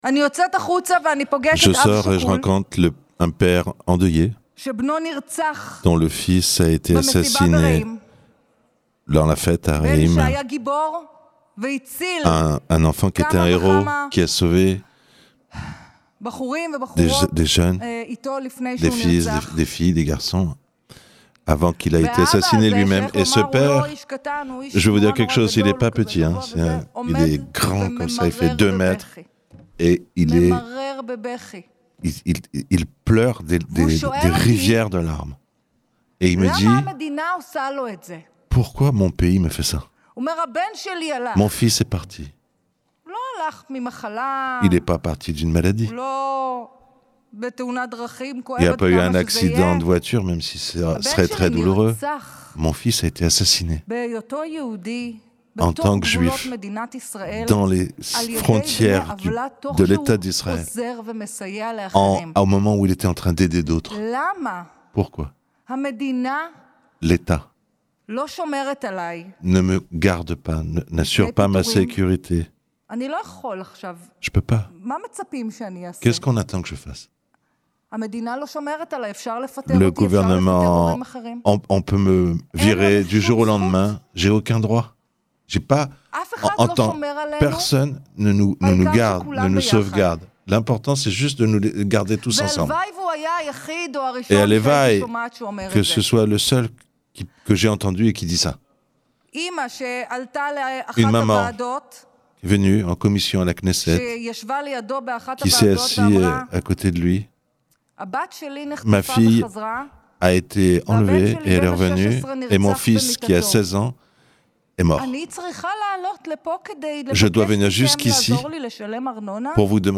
Elle s’appelle Michal Shir, elle est membre de l’opposition, membre de Yesh Atid Elle parle de la tribune de la Knesset.